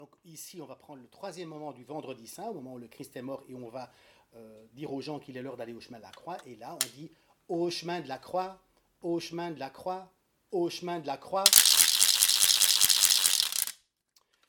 Genre : chant
Type : chanson calendaire
Instrument(s) : crécelles
Lieu d'enregistrement : Institut Supérieur Royal de Musique et de Pédagogie (Namur)
Il s'agit ici de la troisième intervention du Vendredi saint chantée par les garçons.